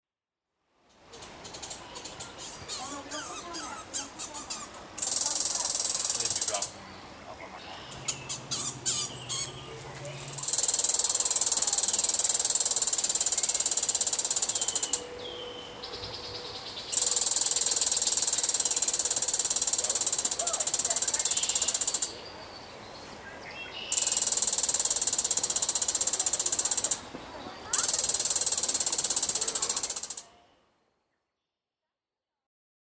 son-ecureuil.mp3